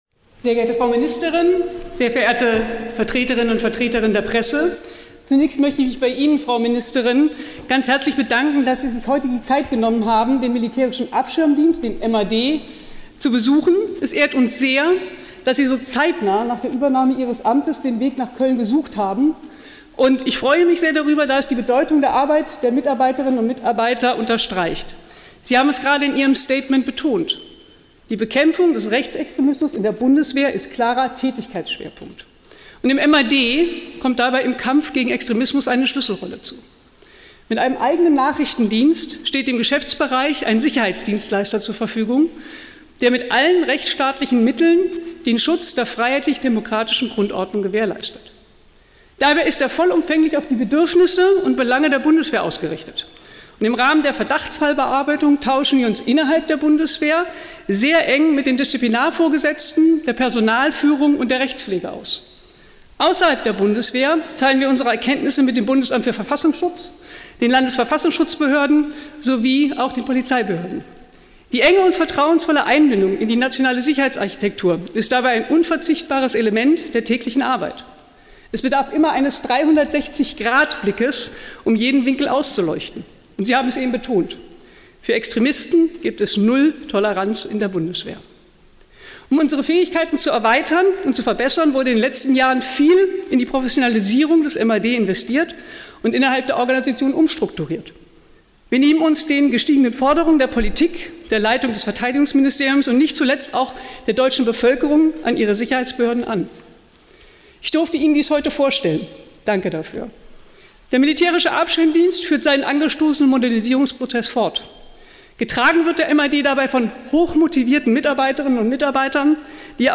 Pressestatement Präsidentin BAMAD Besuch Ministerin